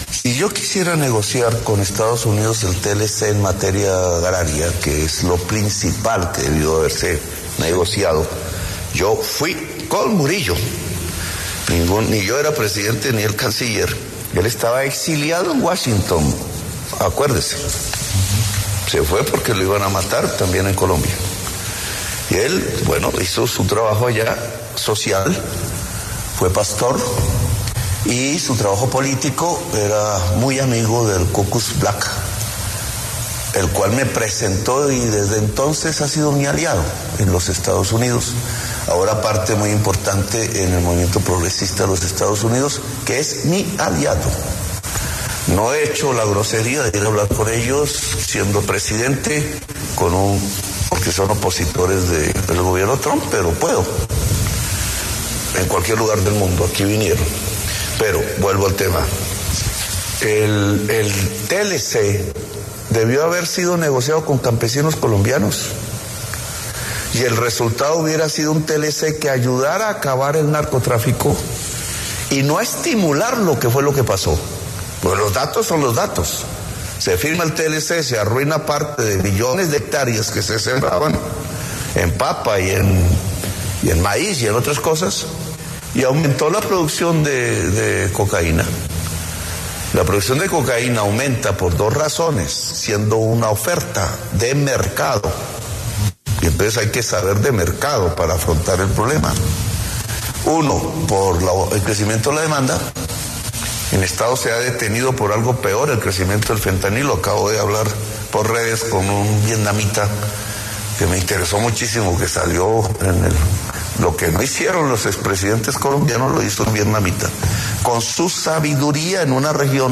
Este lunes, 20 de octubre, el presidente de la República, Gustavo Petro, habló en exclusiva con Daniel Coronell para El Reporte Coronell sobre la escalada en la tensión diplomática entre Estados Unidos y Colombia.